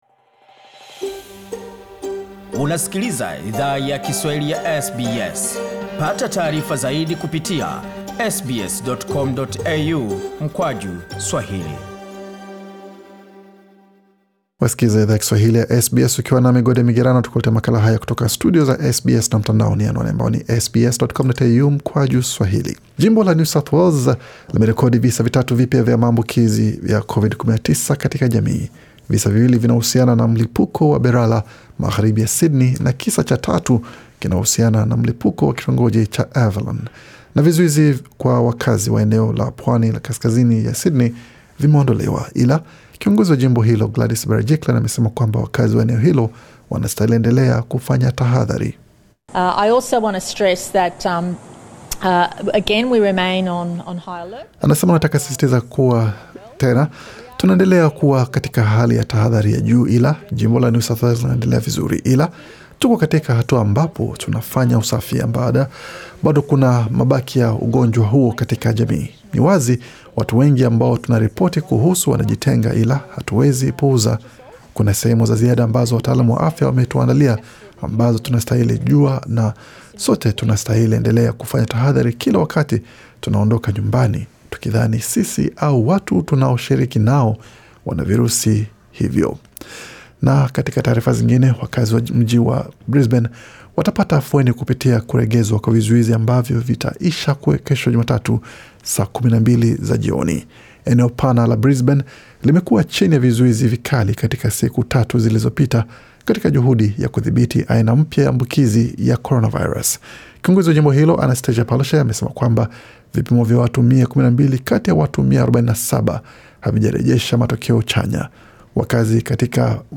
Taarifa ya habari 10 Januari 2021